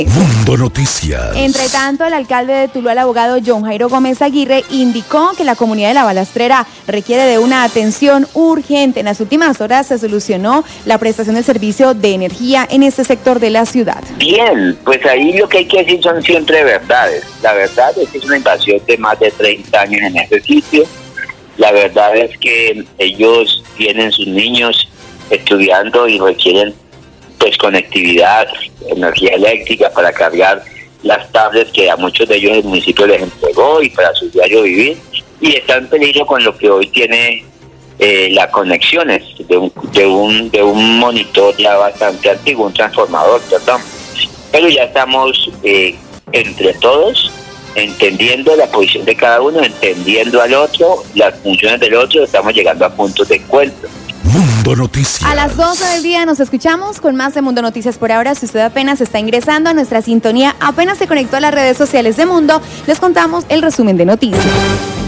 Radio
Declaraciones del alcalde de Tuluá sobre el acuerdo al que se pudo llegar con la comunidad de La Balastrera, que durante el jueves y viernes cerró la vía al corregimiento de Campo Alegre para reclamar el servicio de energía que les había sido suspendido. Según el alcalde la comunidad necesita atención urgente y el proceso que se debe adelantar en el sector es largo y complejo, sin embargo lograron acuerdos que permitieron levantar los bloqueos.